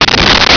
Sfx Whoosh 4804
sfx_whoosh_4804.wav